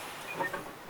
mikä äänipari tuo on
Mutta ääni kuulostaa keinotekoiselta.